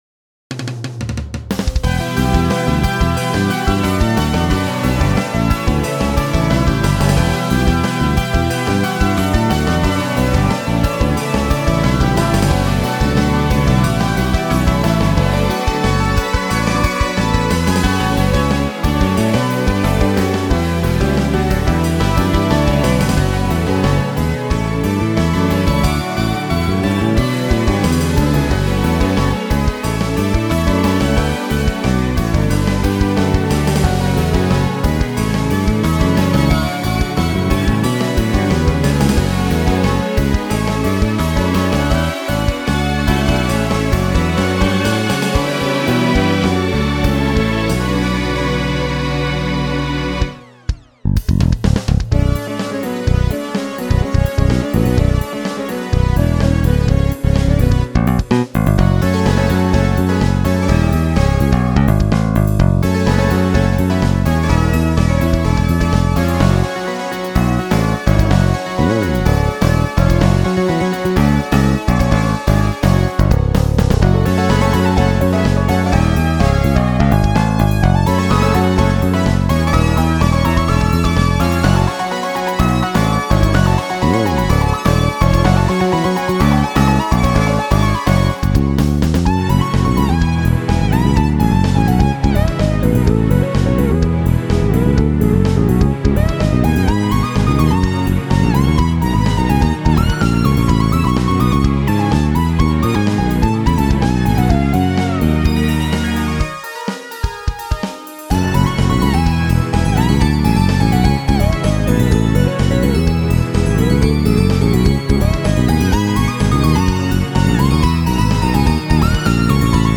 • The Art of It makes makes almost exclusive use of 31edo diasem: